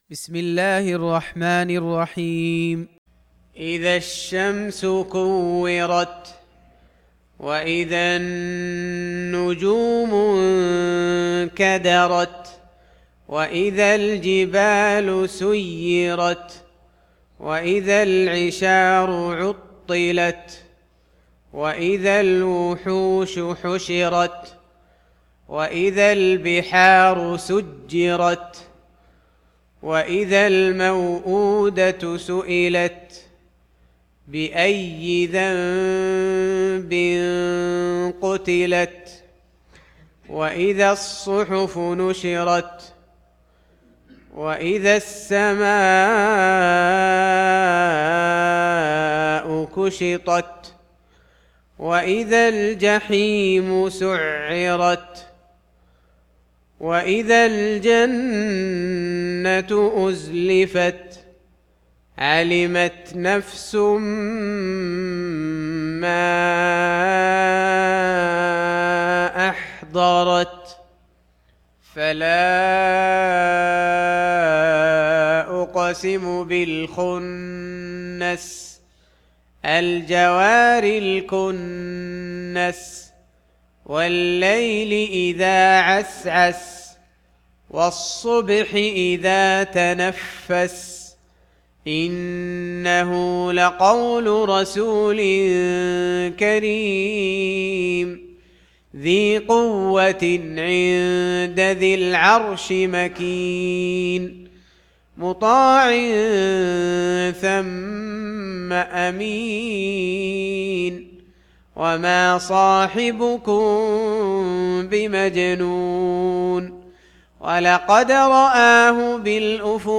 Audio Quran Tarteel Recitation
Surah Sequence تتابع السورة Download Surah حمّل السورة Reciting Murattalah Audio for 81. Surah At-Takw�r سورة التكوير N.B *Surah Includes Al-Basmalah Reciters Sequents تتابع التلاوات Reciters Repeats تكرار التلاوات